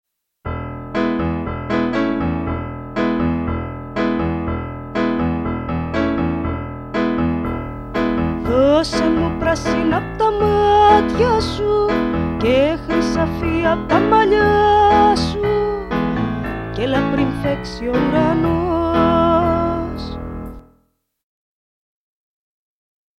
Πρώτα πρέπει να φτάσει στα αφτιά του μουσικού παραγωγού η αρχική ιδέα του συνθέτη και του στιχουργού σε απλή μορφή (συνήθως εκτελείται με ένα όργανο και τη φωνή). Αυτό ονομάζεται δείγμα (demo, από το demonstration = επίδειξη).